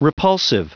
Prononciation du mot repulsive en anglais (fichier audio)
Prononciation du mot : repulsive